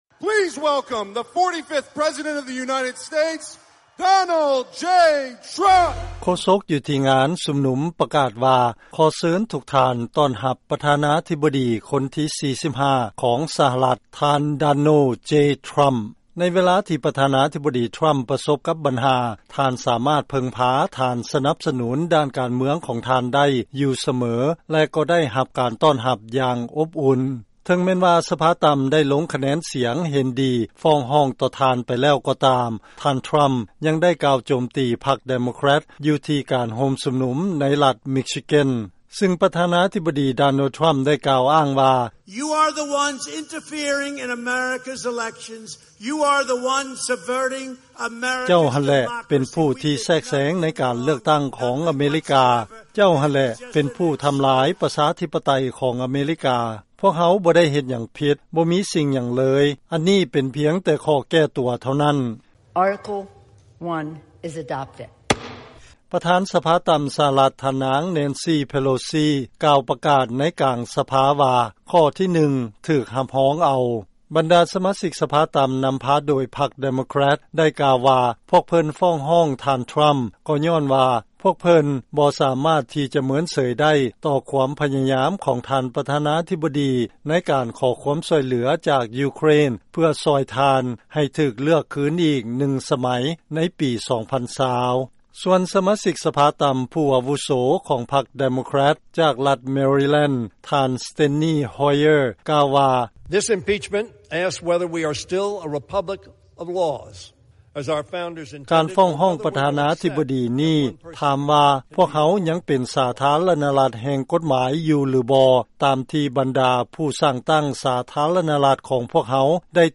ເຊີນຟັງລາຍງານ ຜົນຂ້າງຄຽງ ຈາກການຟ້ອງຮ້ອງ ປ. ທຣຳ ໃນດ້ານການເມືອງ ຈະອອກມາຢ່າງໃດນັ້ນ ບໍ່ມີໃຜຮູ້ຈັກ